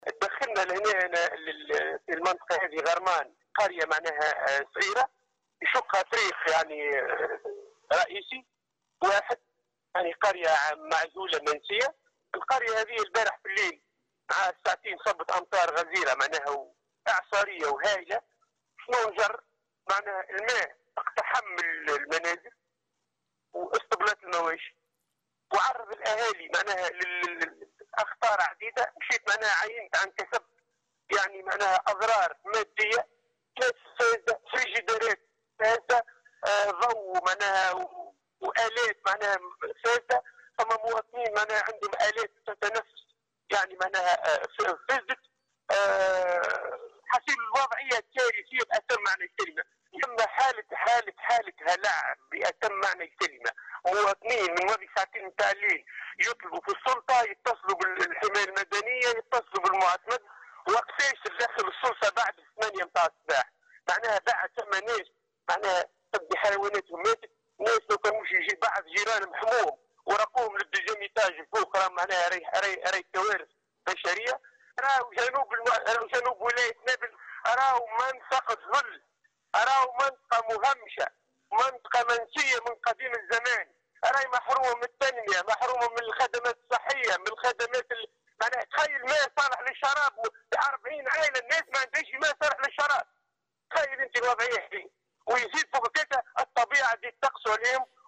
في تصريح لمراسلة "الجوهرة أف أم" بالجهة